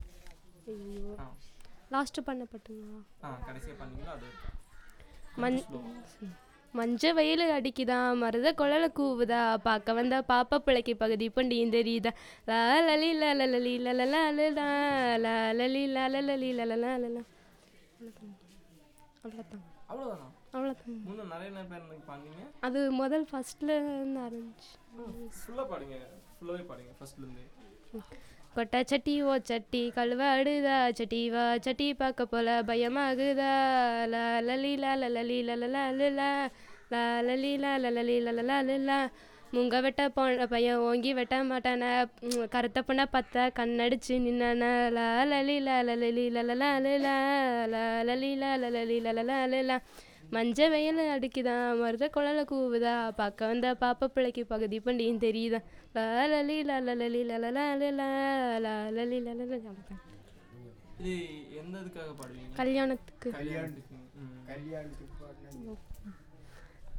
Performance of traditional song
NotesThis is a performance of traditonal marriage song for the purpose of recording. The song is about a boy who goes to forest to cut bamboos with a girl.